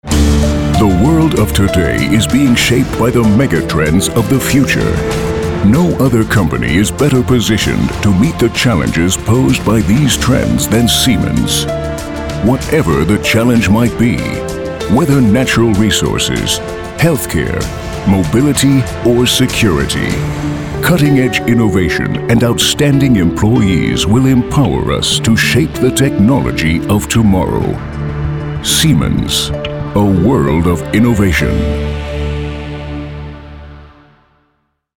amerikanischer Profi-Sprecher.
Sprechprobe: eLearning (Muttersprache):
english (us) voice over talent.